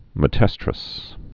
(mĕ-tĕstrəs)